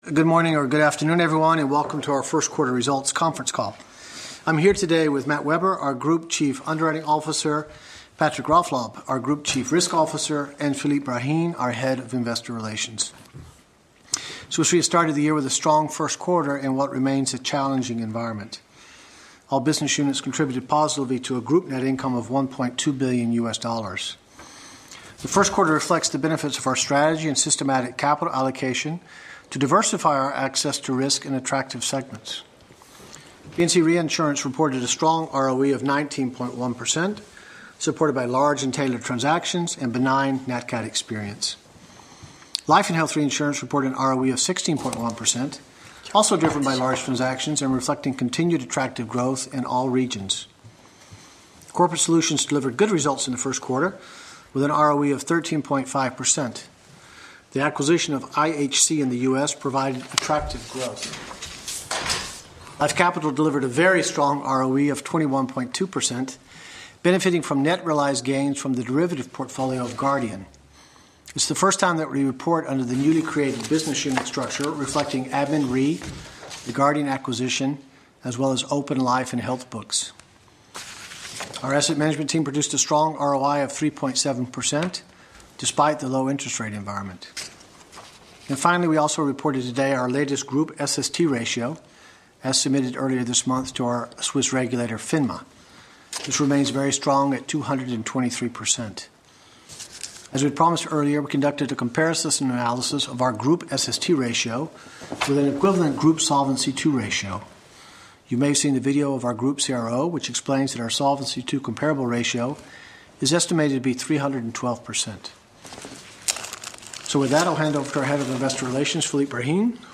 Analysts Conference call recording
2016_q1_qa_audio.mp3